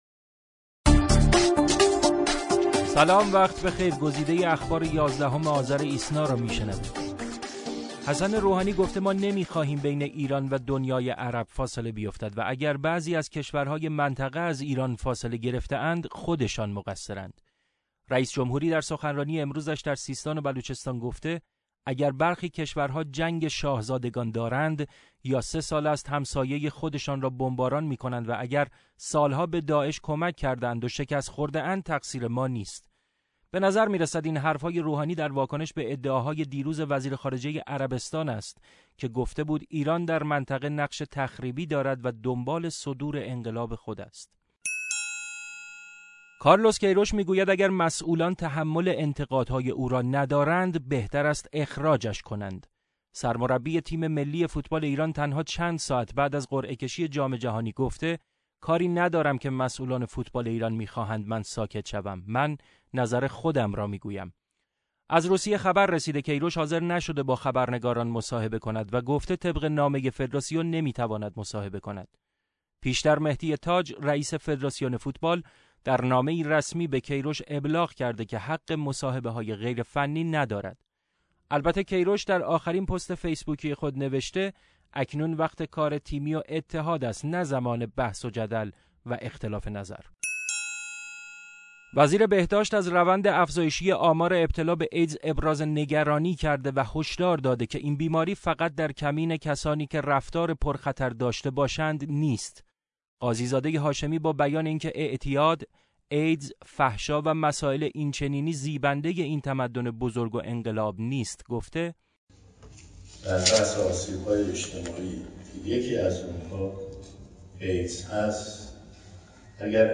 صوت / بسته خبری ۱۱ آذر ۹۶